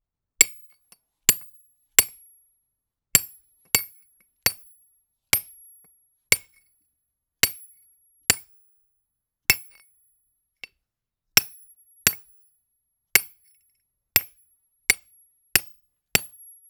Sound of Being Worked by Hammer and Chisel
Rock Hammer Chisel